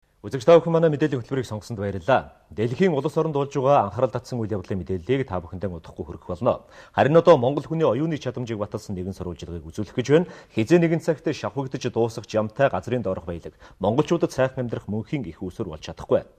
Here’s a recording of part of a news report in a mystery language.
To me it sounds similar to Japanese, but with many hard Rs.
Sounds kinda like Tadjik, spoken in Tadjikistan and surrounding countries.